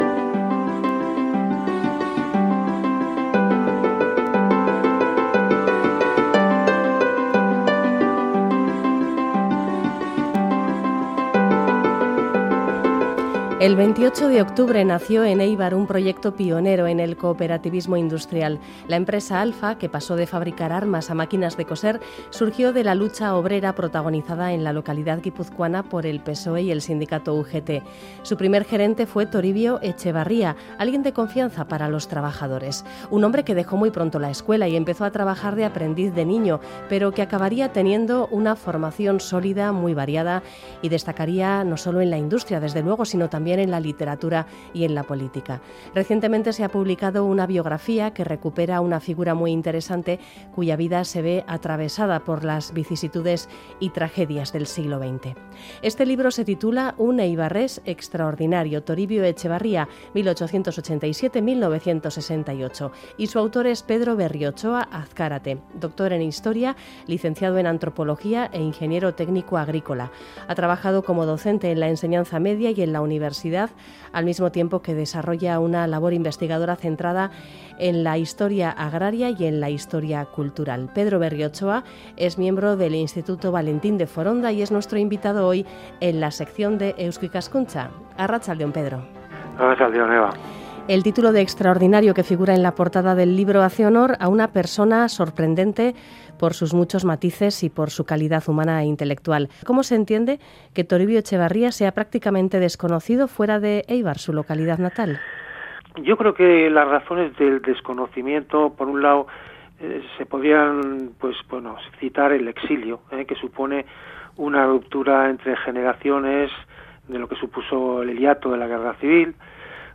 irratsaioa_lmc_toribio.mp3